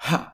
takedamage4.ogg